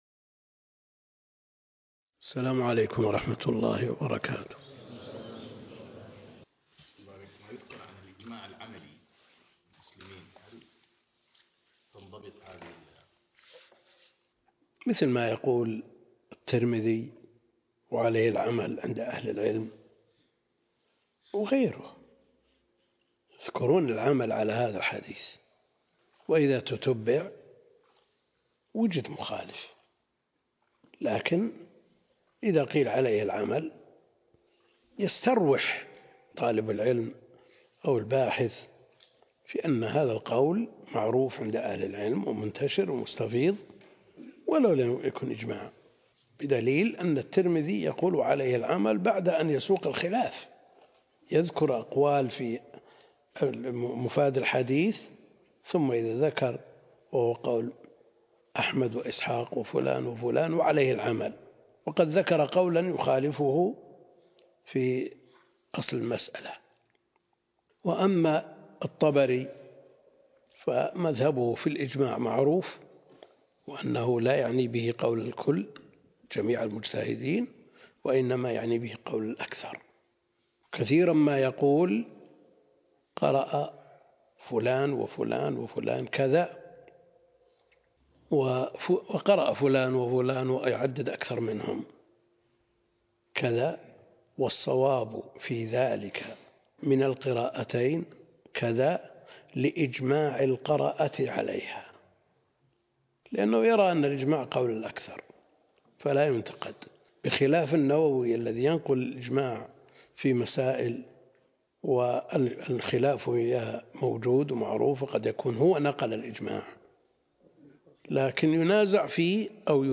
الدرس (16) شرح نونية ابن القيم - الدكتور عبد الكريم الخضير